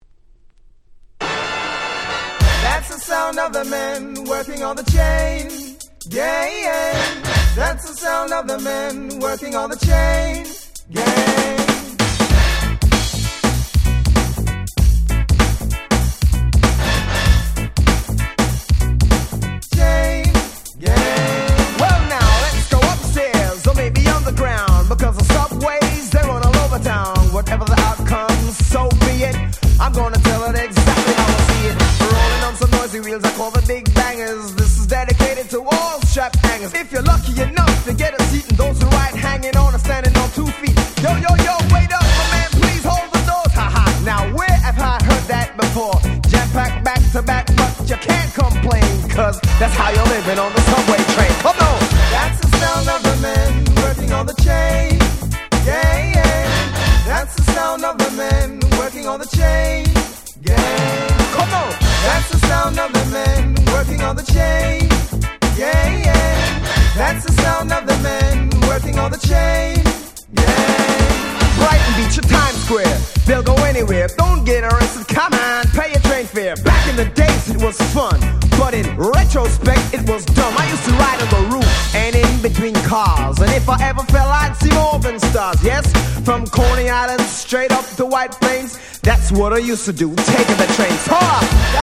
80's ダンスホールレゲエ Reggae